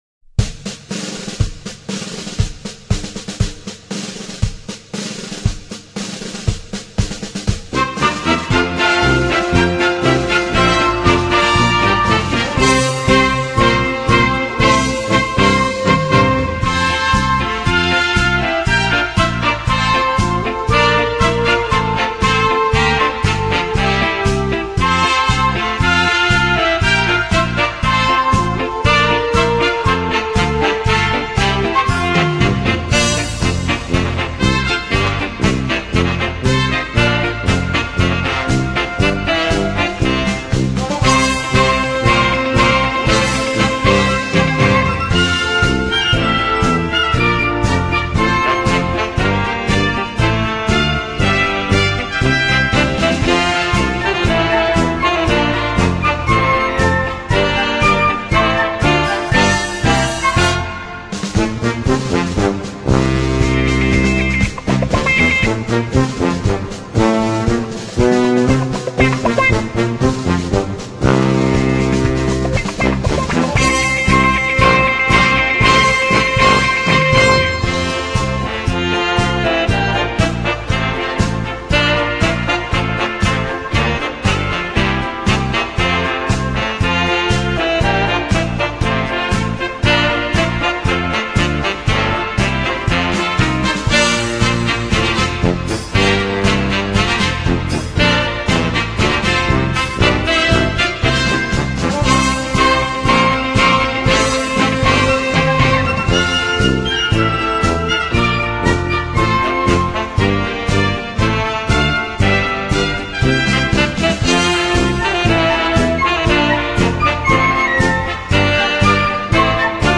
マーチバージョン